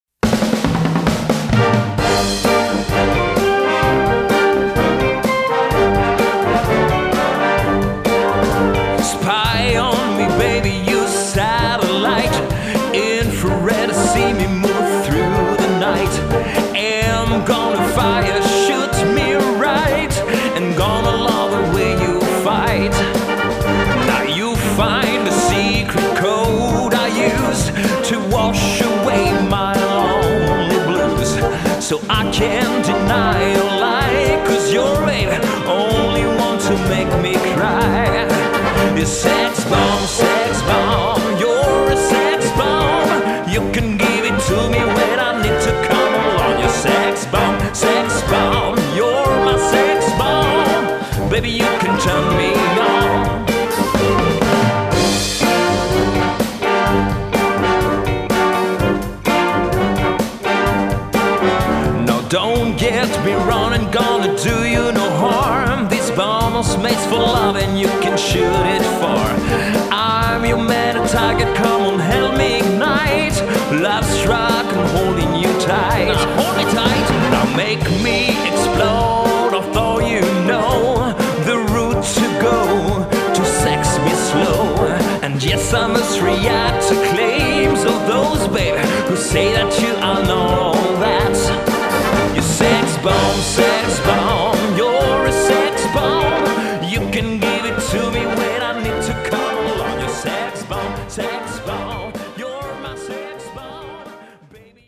Gattung: für Solo Gesang und Blasorchester
Besetzung: Blasorchester